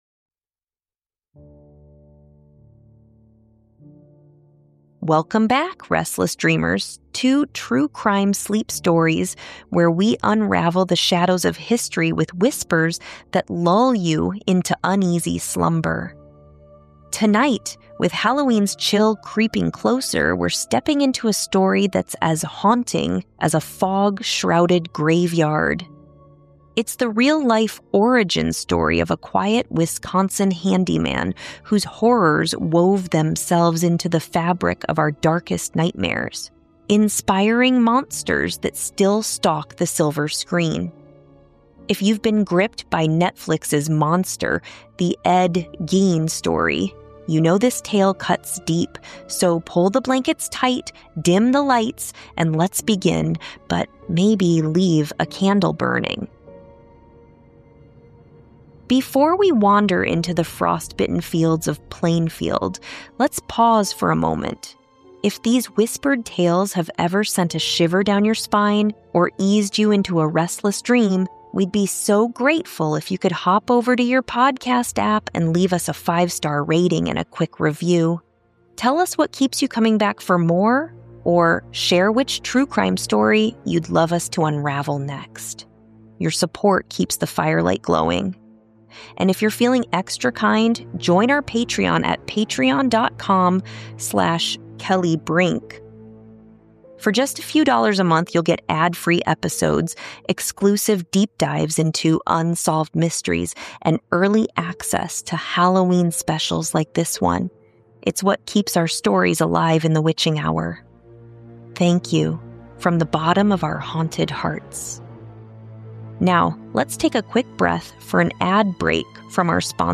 Told in a soothing yet eerie tone, this episode explores the isolation, obsession, and fractured psyche that turned a shy handyman into a legend of horror.